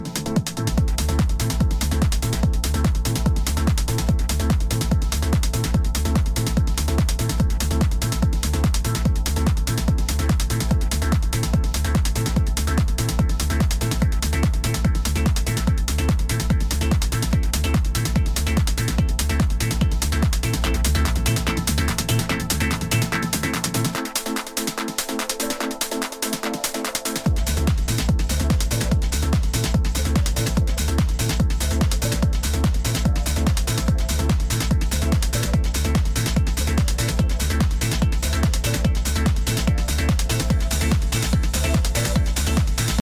Techno